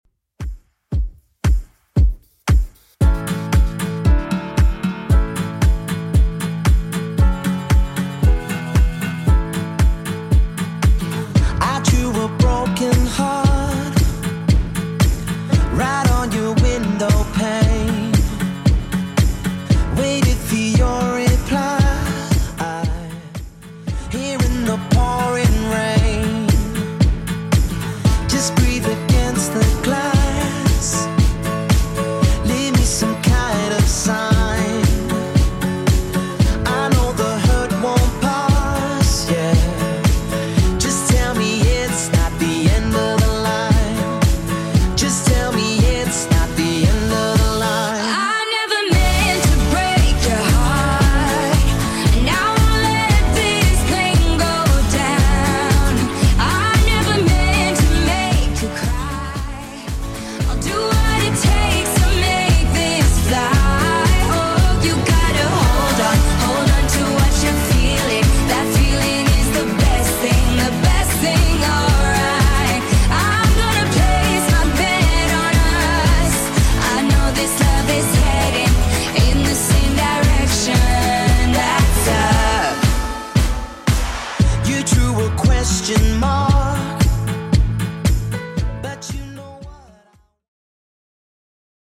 Genre: 60's
BPM: 197